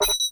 bulkhead sfx
click_electronic_04.wav